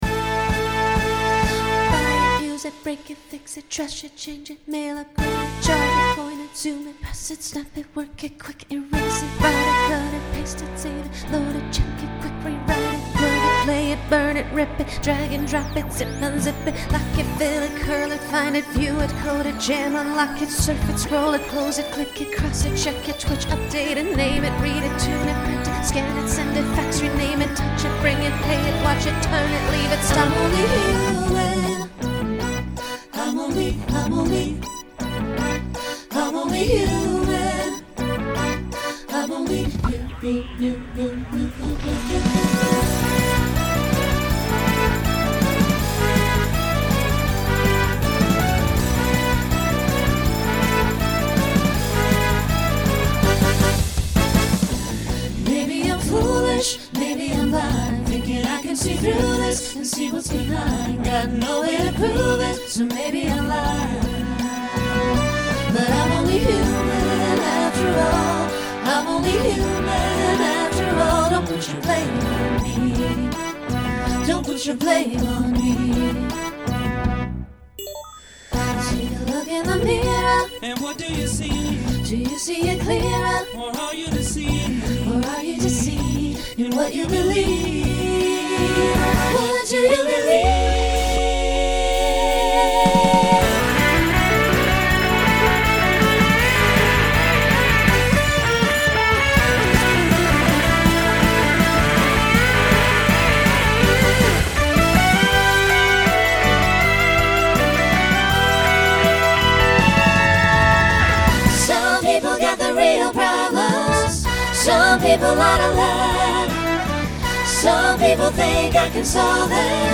Starts with an extended solo to facilitate costume change.
Pop/Dance
Voicing SATB